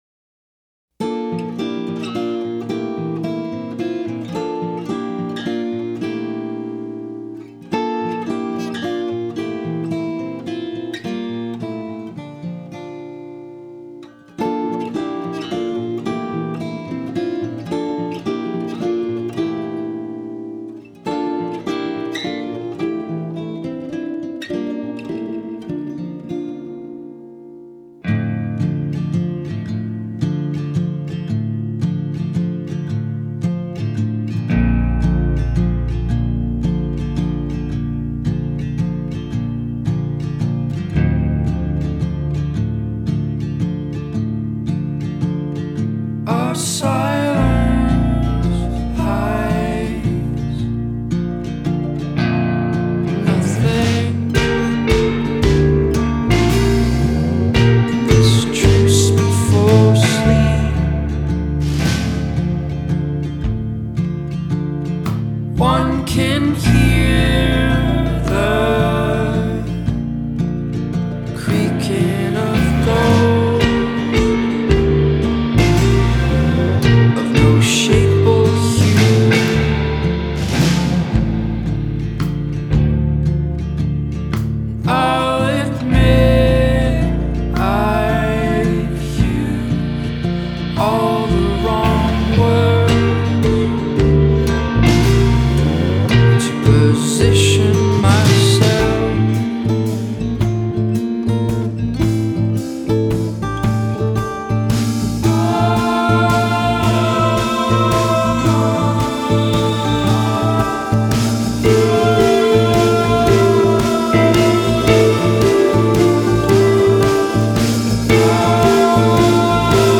folk chaleureuse mais néanmoins plus torturée
aux rythmiques syncopées.